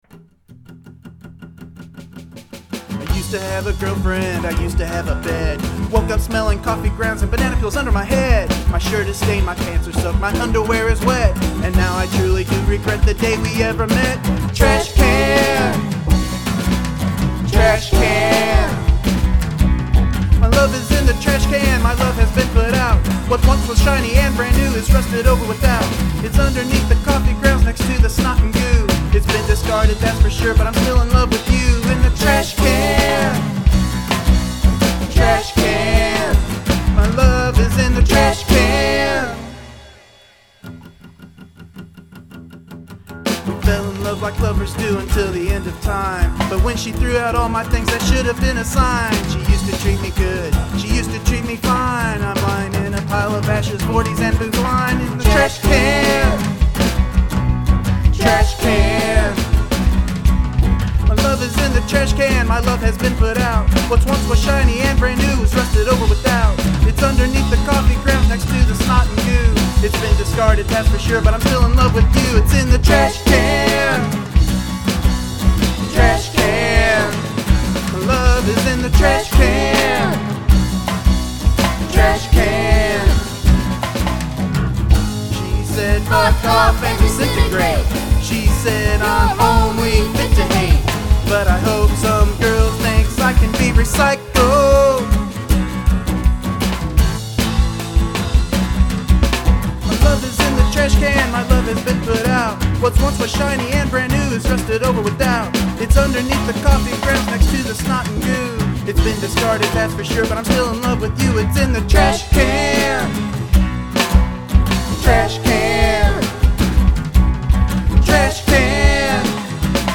Her EP was acoustic punk.
Guitar/Vox
Drums
Yes, real drums.
This has a great vibe. Frantic, acoustic punk. The 'live' drums are a big bonus, but the vocals and backing vox are just great.